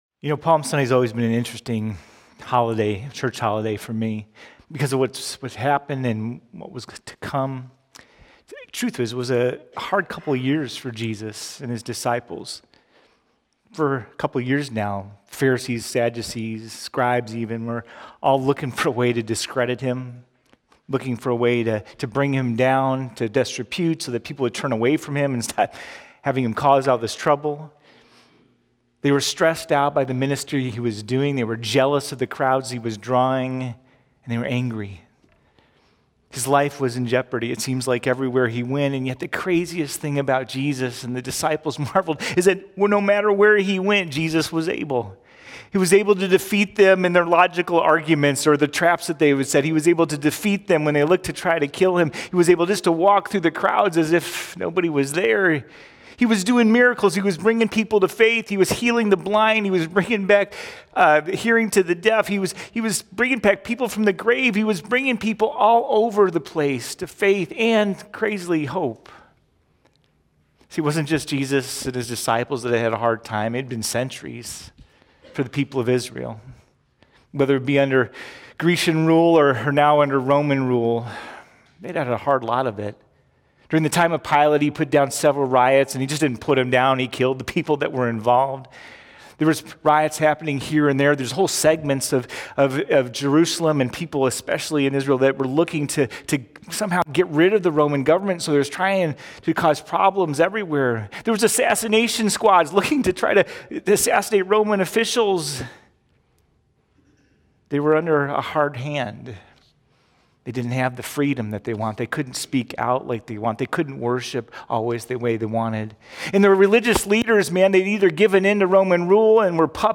42-Sermon.mp3